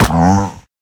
Minecraft Version Minecraft Version latest Latest Release | Latest Snapshot latest / assets / minecraft / sounds / mob / wolf / big / hurt1.ogg Compare With Compare With Latest Release | Latest Snapshot
hurt1.ogg